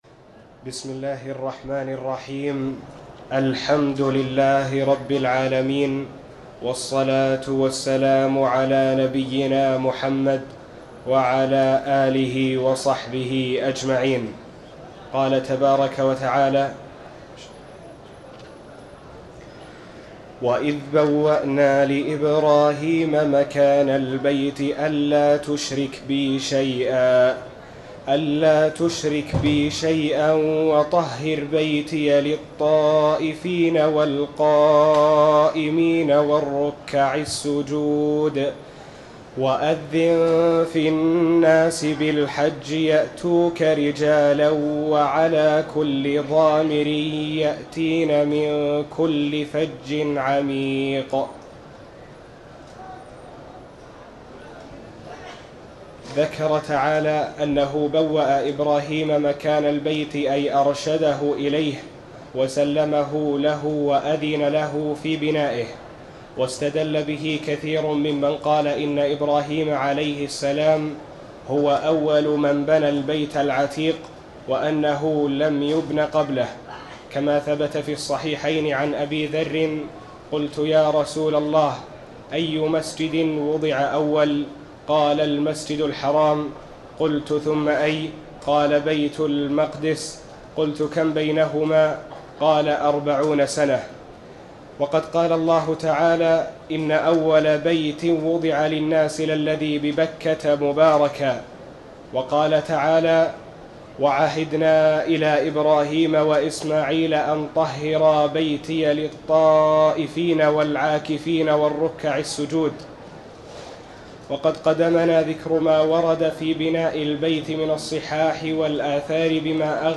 تاريخ النشر ٢١ ذو القعدة ١٤٤٠ هـ المكان: المسجد الحرام الشيخ